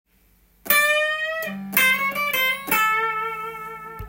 混ぜたギターフレーズ集
Aマイナーペンタトニックスケールとメジャーペンタトニックスケールを
弾きながらクロマチックスケールを混ぜたフレーズ。